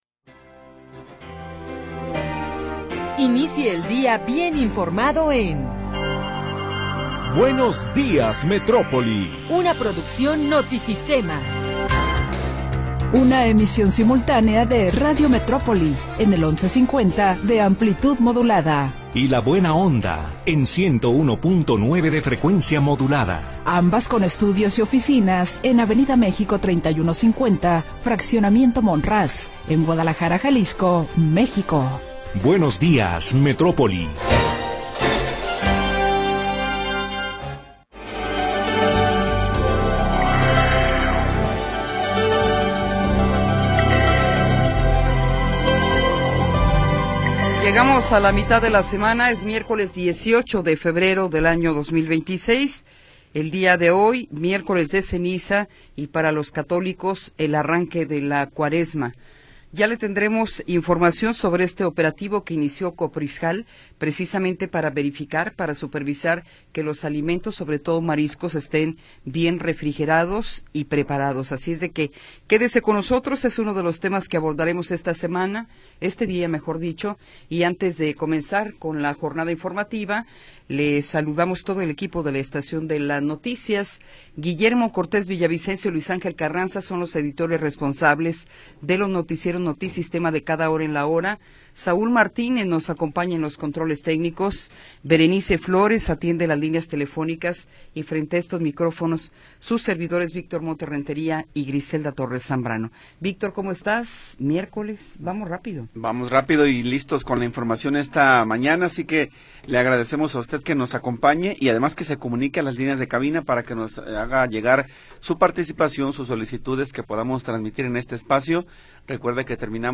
Primera hora del programa transmitido el 18 de Febrero de 2026.